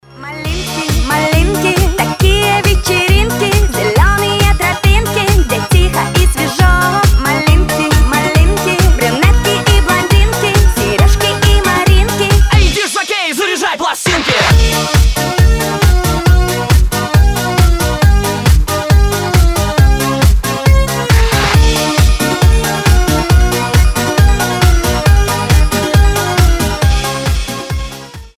Танцевальные рингтоны
веселые